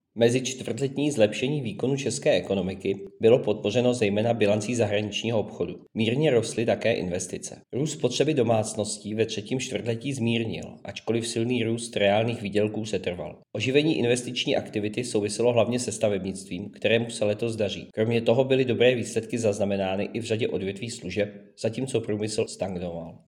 Vyjádření Jaroslava Sixty, místopředsedy Českého statistického úřadu, soubor ve formátu MP3, 912.19 kB